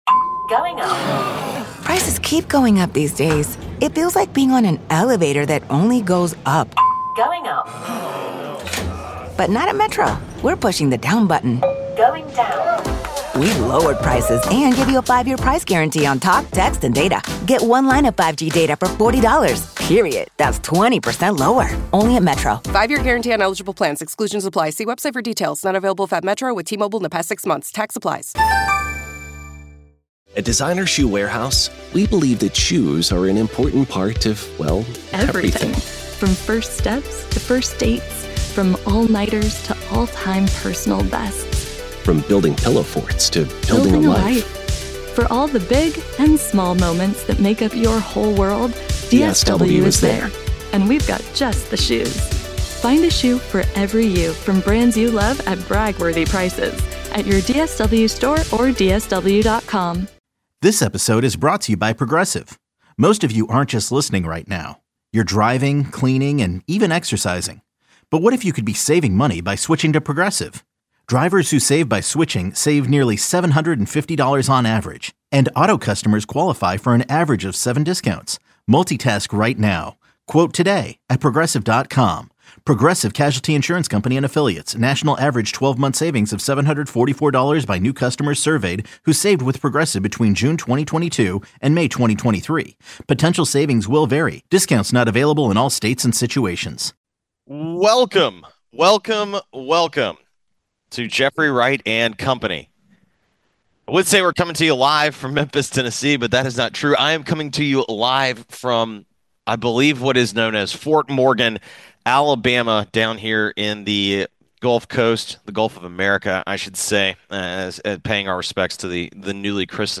now hosts every afternoon with a rotating cast of co-hosts
… continue reading 469 episodes # Sports # Audacy